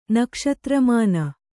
♪ nakṣatra māna